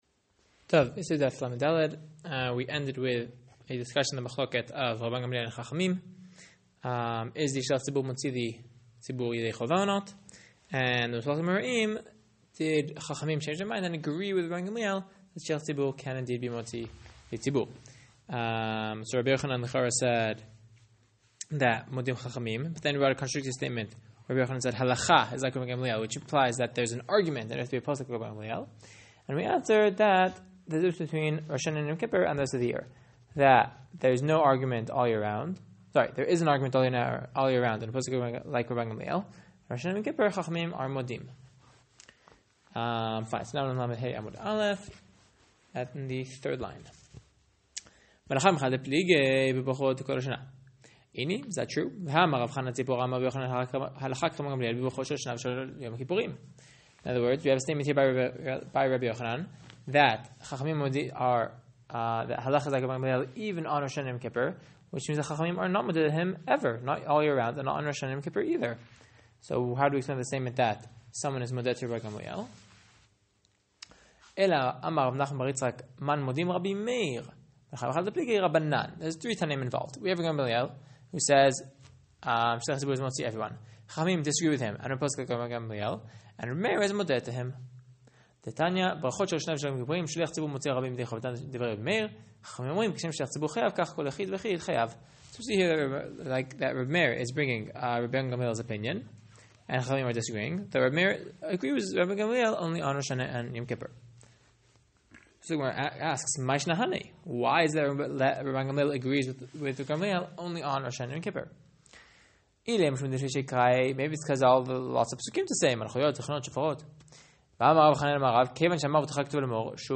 Lesson 34